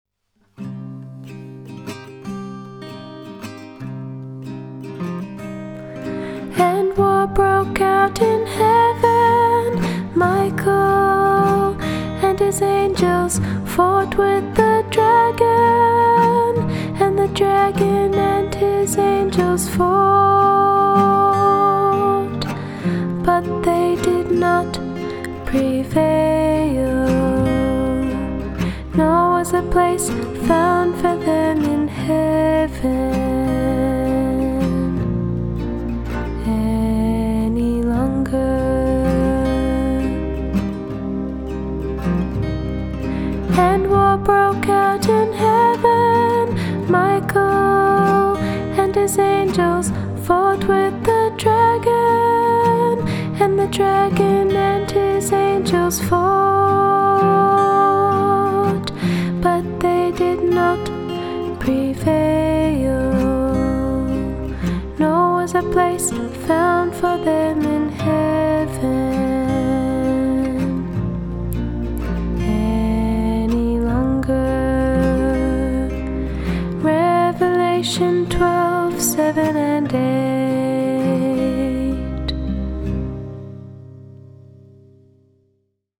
Vocalist
Keyboard
Guitar